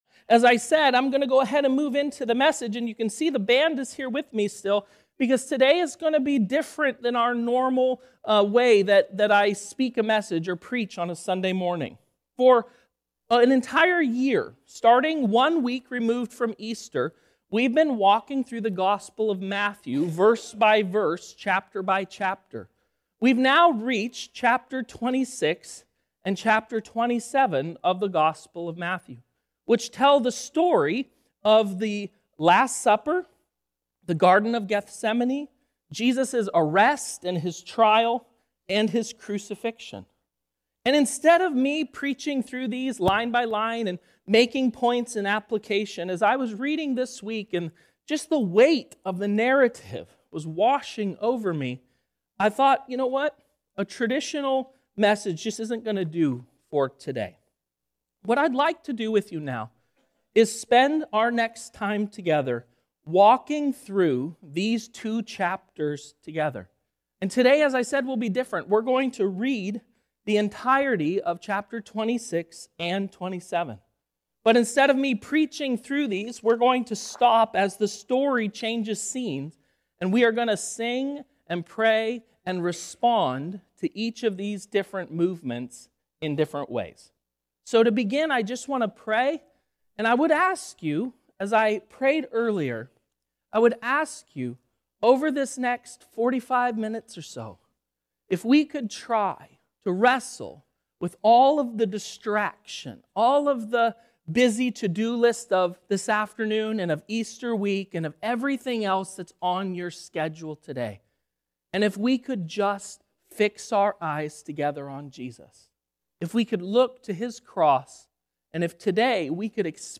Reading through Matthew 26 & 27 with response through reflection, communion, prayer and song to help prepare our hearts for Easter.
A Service of Reflection